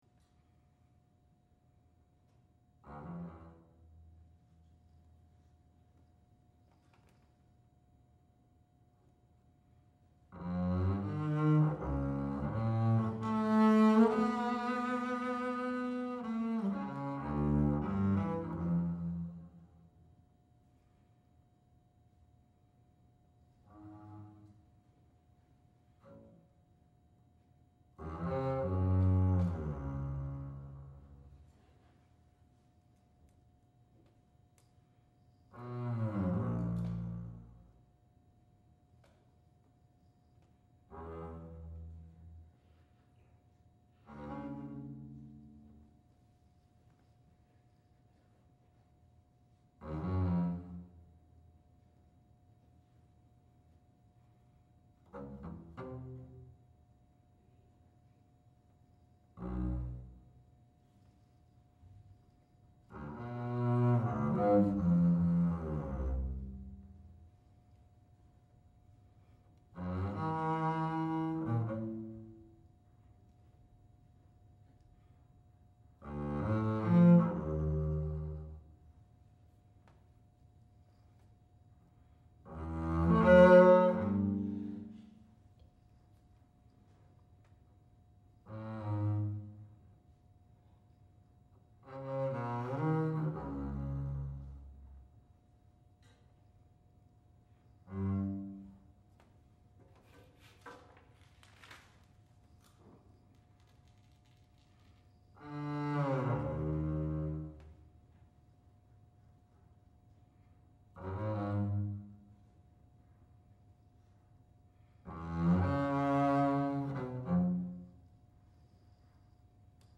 contrabass solo